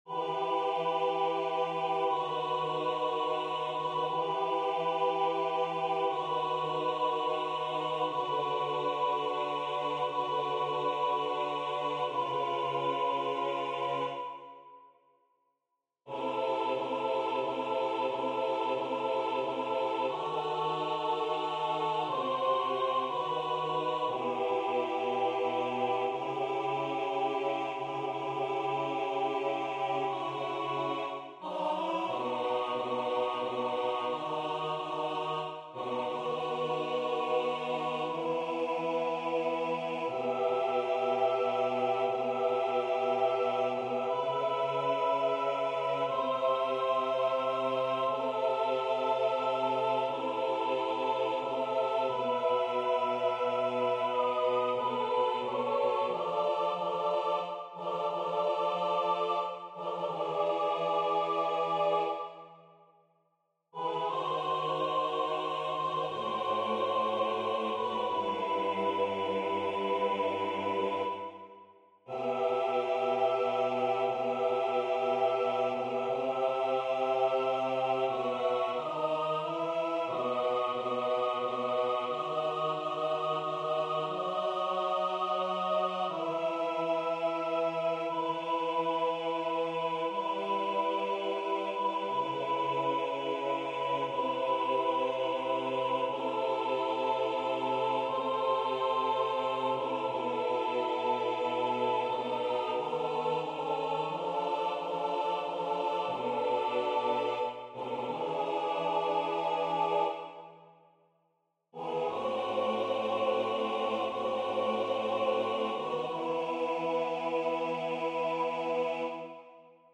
Tutti
Mp3 version piano
MP3 versions rendu voix synth.